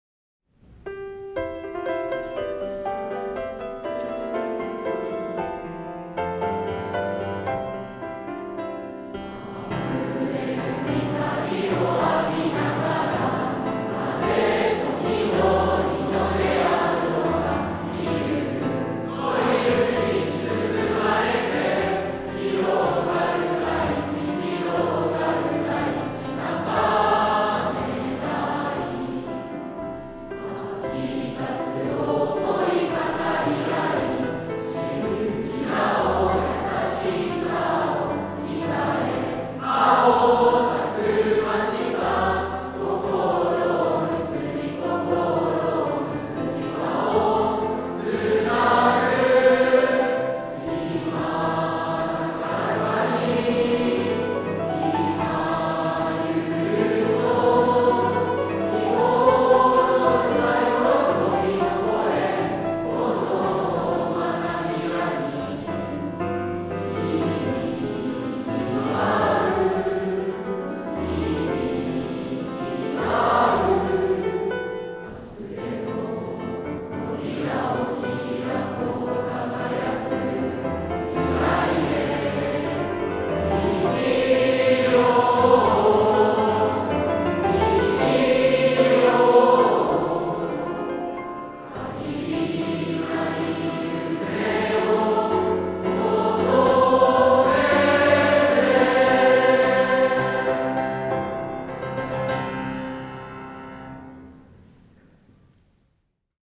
中根台中学校校歌（平成26年度合唱祭）(音楽ファイル(MP3):2,497KB)
作詞：中根台中学校
作曲：三枝　成彰
koukaheisei26nendogasshousai.mp3